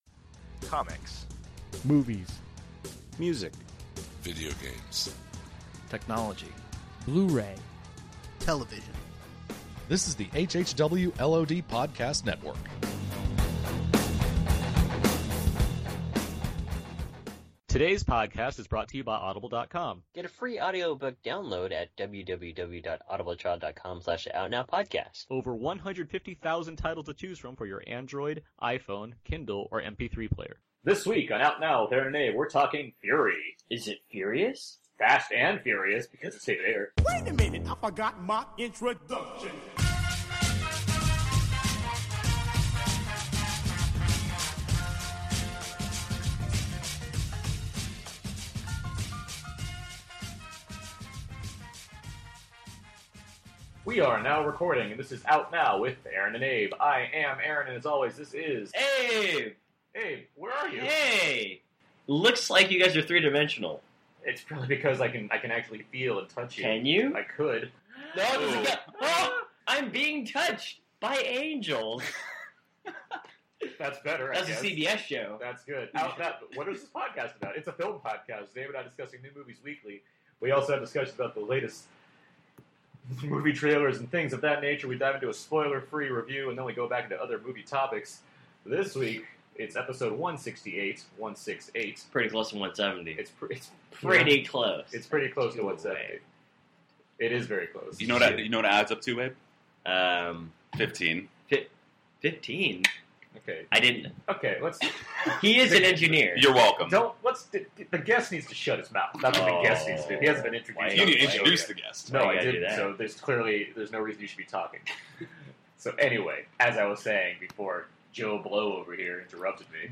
as we recorded a special ‘in person’ episode this week